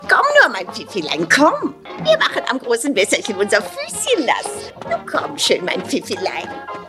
- Frau mit Hund